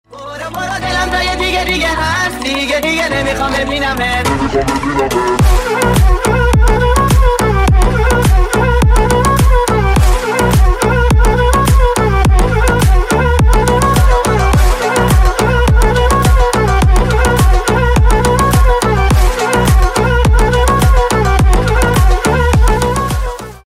Клубные Рингтоны » # Восточные Рингтоны
Танцевальные Рингтоны